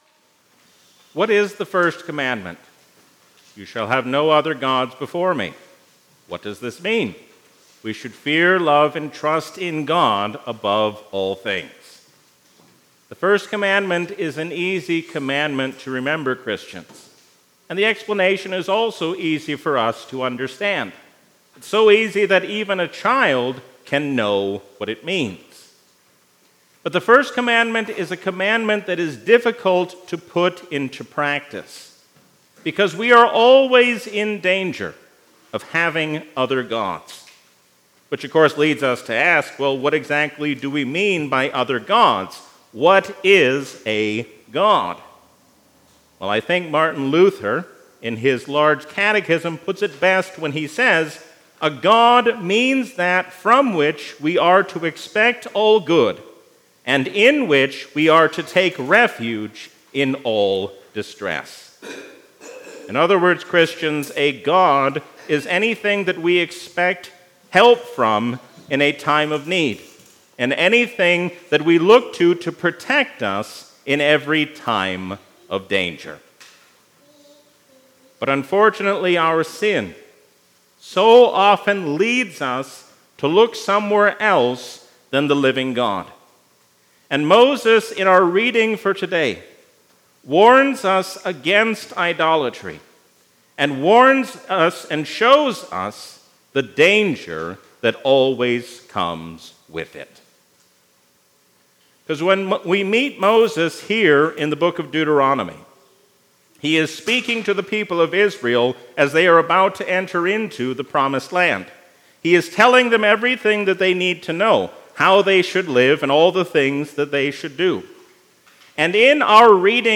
A sermon from the season "Trinity 2023." Do not turn to the things of this world, because they cannot help.